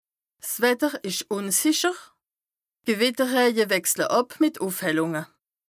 Bas Rhin
Ville Prononciation 67
Strasbourg